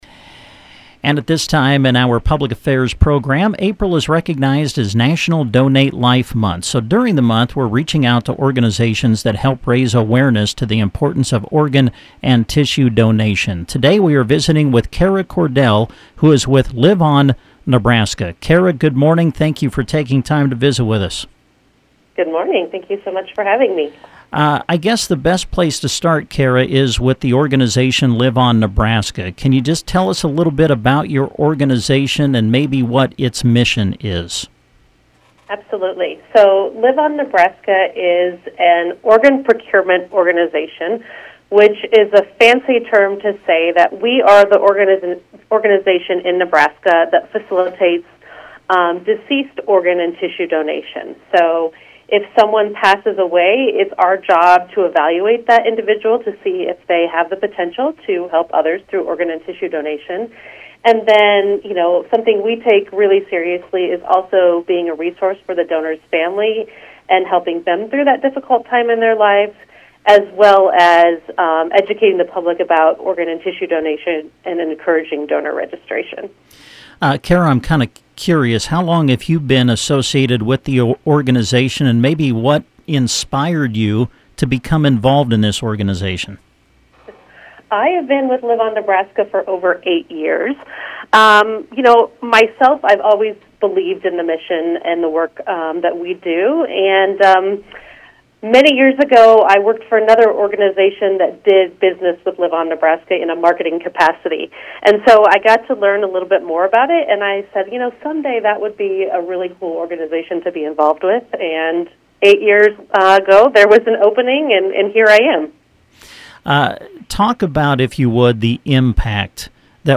LIVE-ON-NE-INTERVIEW_.mp3